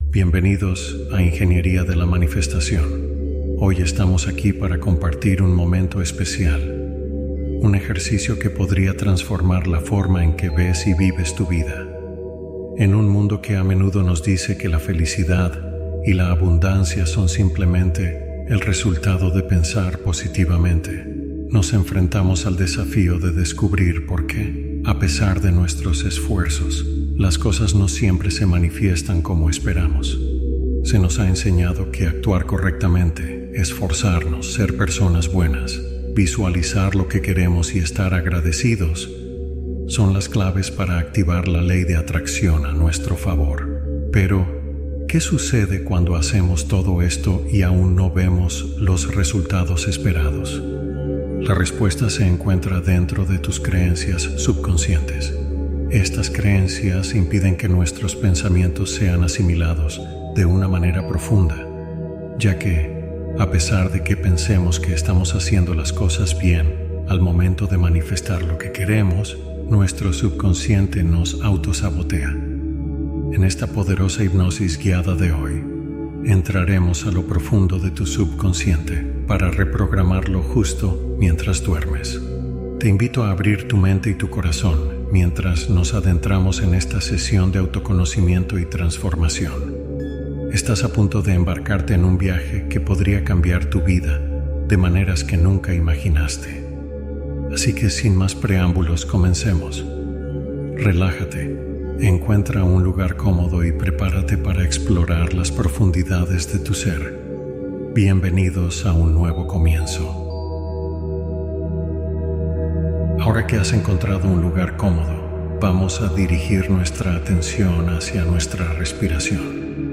Hipnosis suave: explorar creencias y deseos con atención guiada